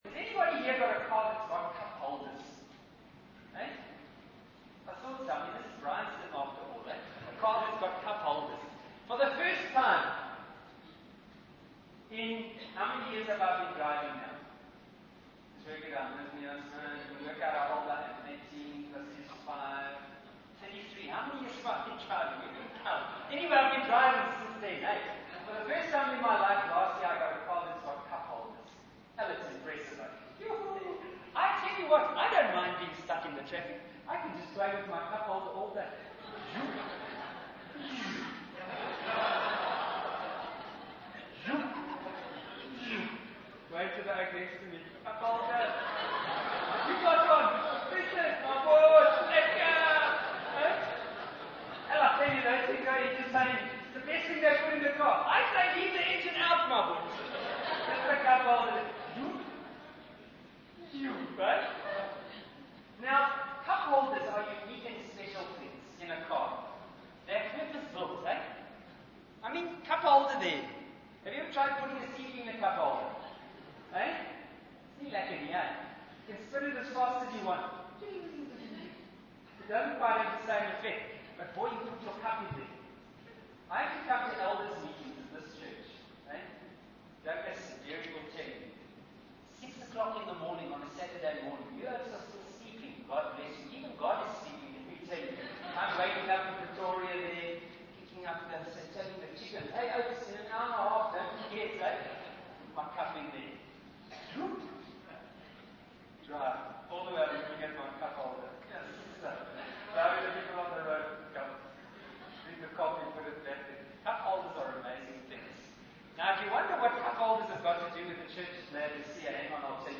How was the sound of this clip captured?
This sermon is the last sermon in a series on the 7 Churches of Revelation 2 and 3. The title of the sermon is "Not fit for consumption". It was preached at Bryanston Methodist Church in South Africa on the 21st of August 2005.